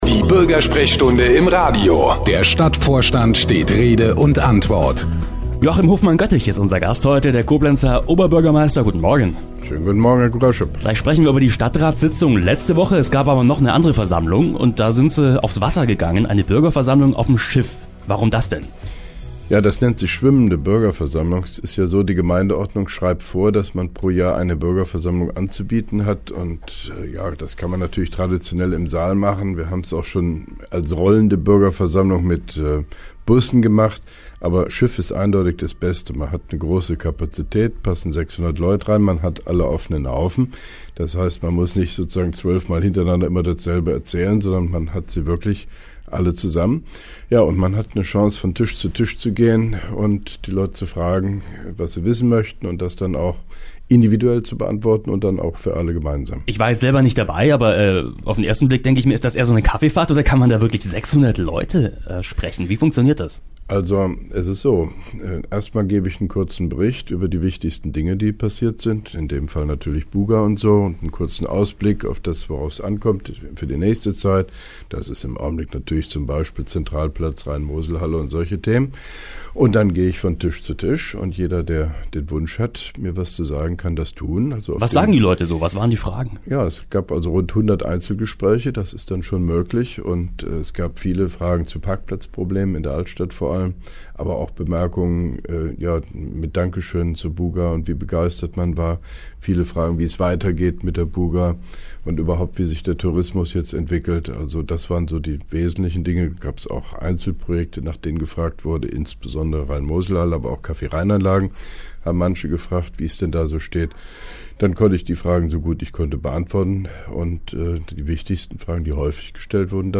(2) Koblenzer Radio-Bürgersprechstunde mit OB Hofmann-Göttig 15.11.2011
Interviews/Gespräche